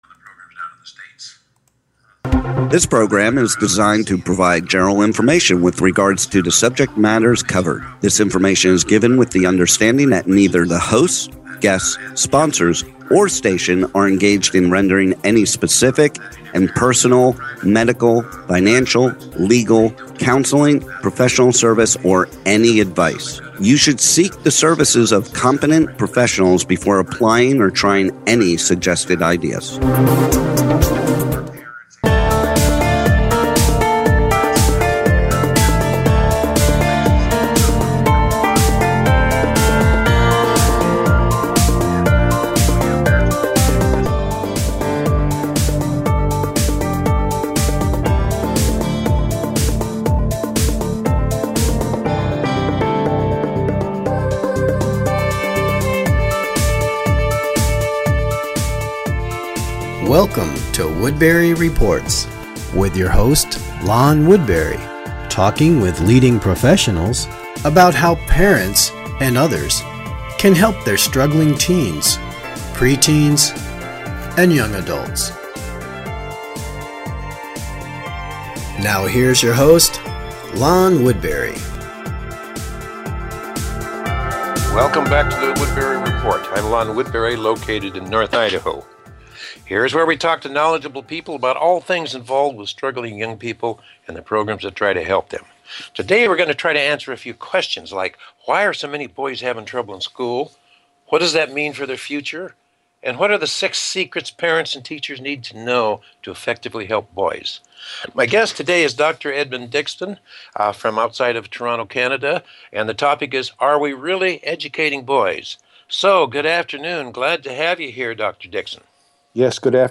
Listen to this intriguing interview about Educating Boys.